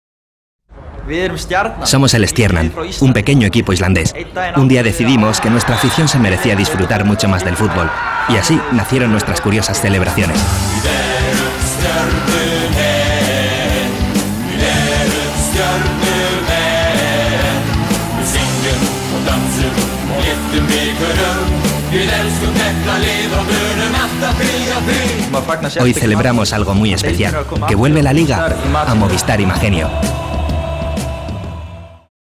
voice over actor working since 2003 for almost all Spanish TV channels.
kastilisch
Sprechprobe: Werbung (Muttersprache):